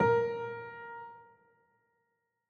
files AfterStory/Doki Doki Literature Club/game/mod_assets/sounds/piano_keys
A4sh.ogg